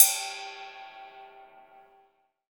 D2 RIDE-10-R.wav